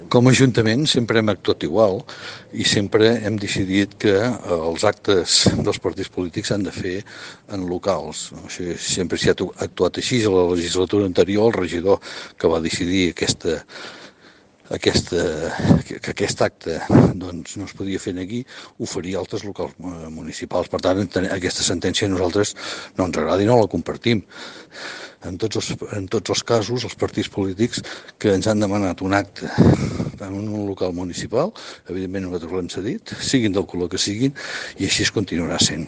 Així doncs, tal i com la sentència sosté que “la resposta sembla ser una opinió personal que no pot servir per justificar la denegació d’una sol·licitud que pot afectar a l’exercici dels drets fonamentals” i que “la sol·licitud va ser ocupar la via pública per un partit polític amb finalitat informativa”, l’Alcalde de Torroella de Montgrí, Jordi Colomí, ha dit en declaracions a Ràdio Capital que això no es permet “a cap partit polític, no es va denegar pel fet que fos Ciutadans”, sinó perquè “els actes polítics sempre es fan en locals.”